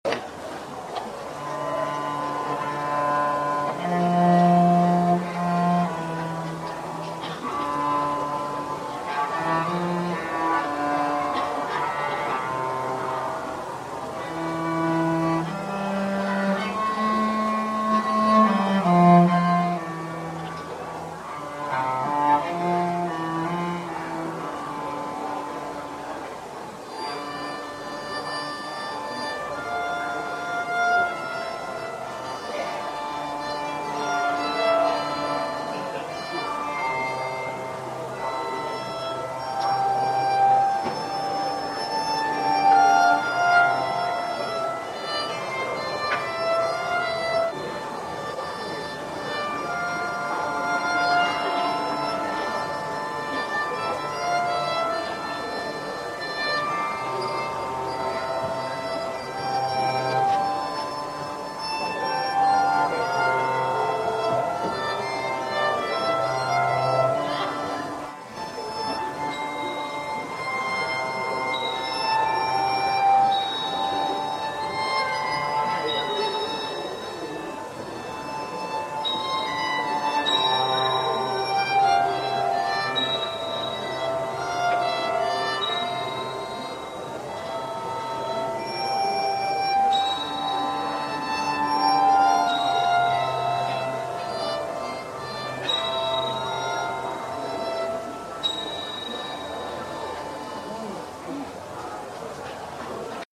SALTERIO AD ARCO
Il suo timbro è penetrante e suggestivo, e ricorda da vicino quello di una ribeca: grazie al gran numero di corde che vibrano per simpatia con quella sollecitata dall'arco (questa a sua volta risuona liberamente ed a lungo dal momento che non viene tastata), il suono prodotto è ricchissimo di armonici e sembra echeggiare all'interno di uno spazio ampio e vuoto, come quello di una cattedrale.
salterio ad arco contralto
viola da gamba
La qualità audio è molto bassa in quanto la demo è tratta da una VHS registrata all'aperto